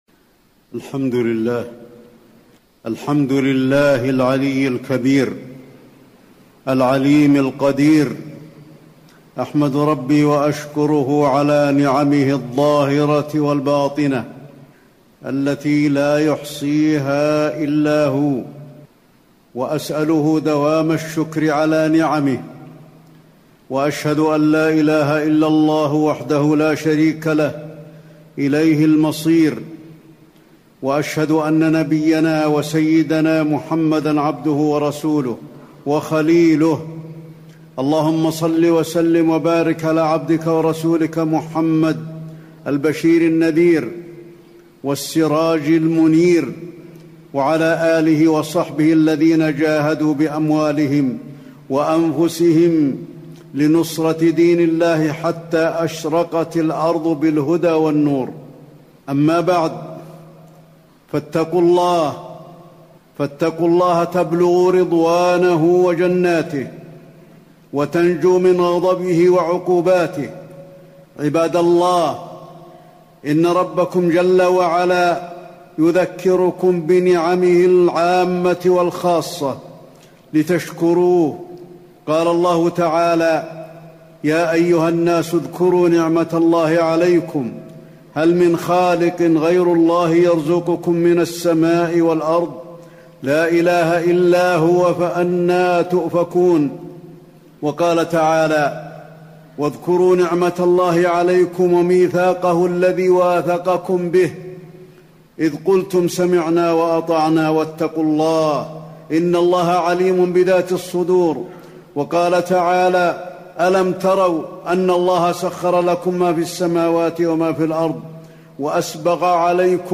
تاريخ النشر ٢١ صفر ١٤٣٩ هـ المكان: المسجد النبوي الشيخ: فضيلة الشيخ د. علي بن عبدالرحمن الحذيفي فضيلة الشيخ د. علي بن عبدالرحمن الحذيفي وجوب شكر المنعم سبحانه على نعمه